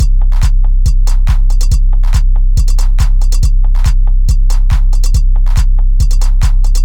beat beats drumkit fast Gabber hardcore House Jungle sound effect free sound royalty free Music